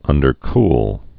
(ŭndər-kl)